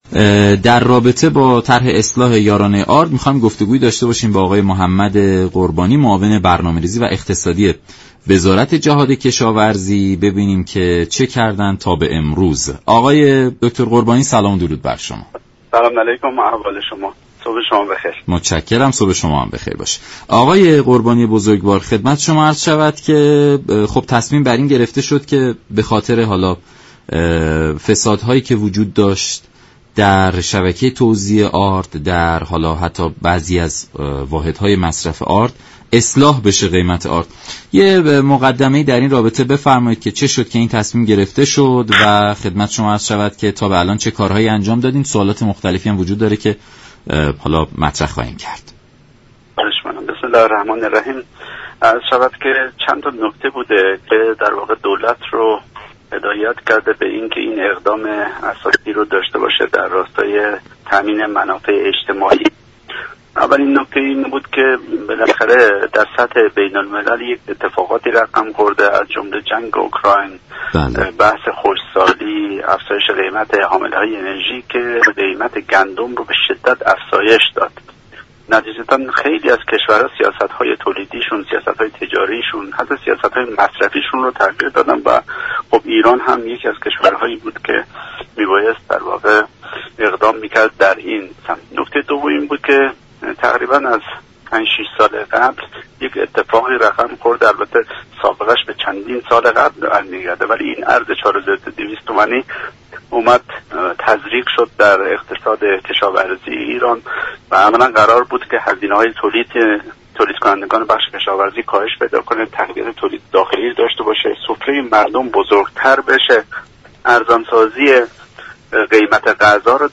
معاون برنامه ریزی و اقتصادی وزارت جهاد كشاورزی گفت: طرح اصلاح یارانه آرد هیچ محدودیتی را برای خرید مصرف كنندگان به وجود نمی آورد و مجلس یارانه این محصول را تا پایان سال تامین كرده است.